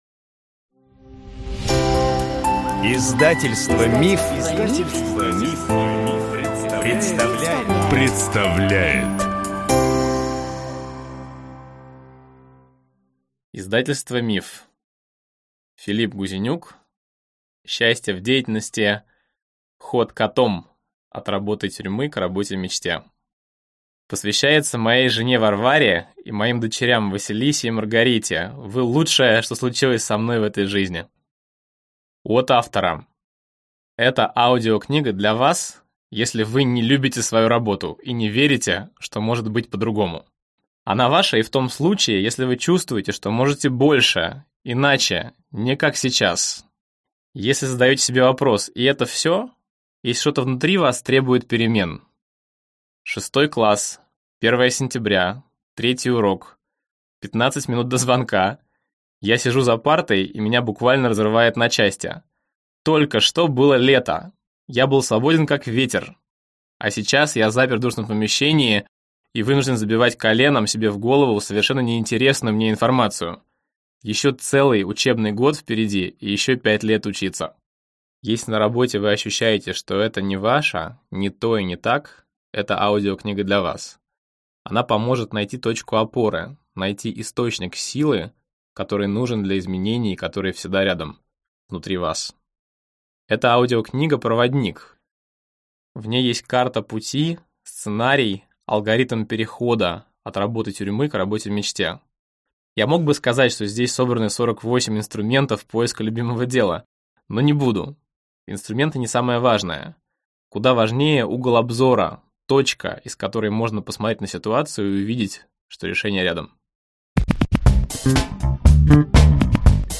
Аудиокнига Счастье в деятельности. Ход котом: от работы-тюрьмы к работе-мечте | Библиотека аудиокниг